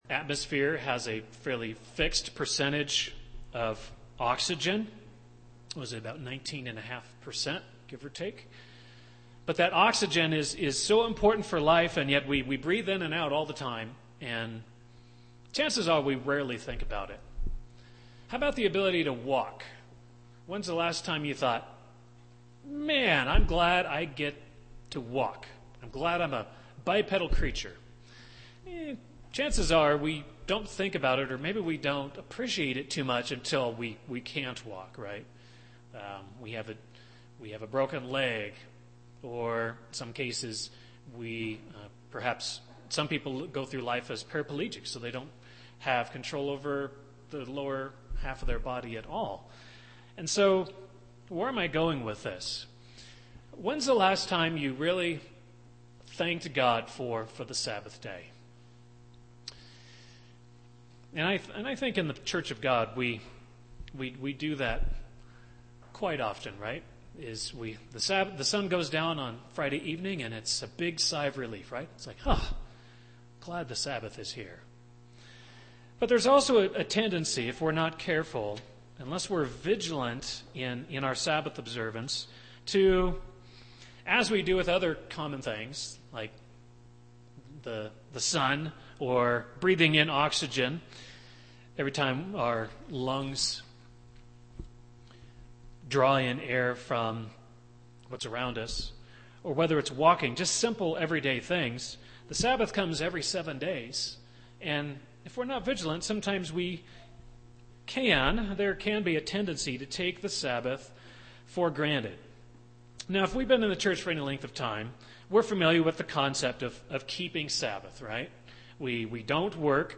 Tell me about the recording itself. Given in Loveland, CO